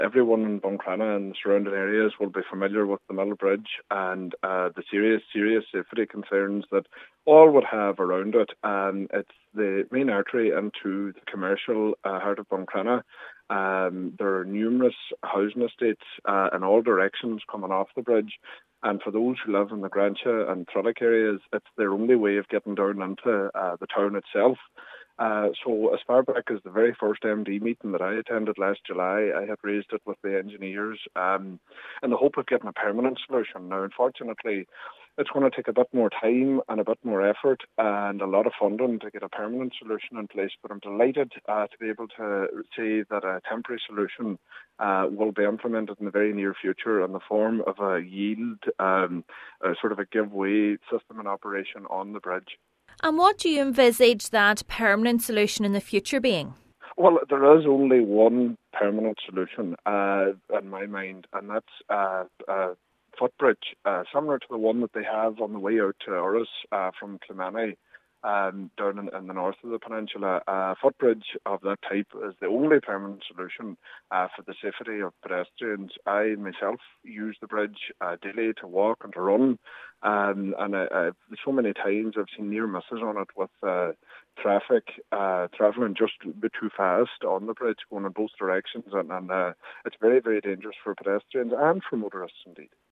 Councillor Fionán Bradley while welcoming the works, says he will continue to lobby for a permanent resolution: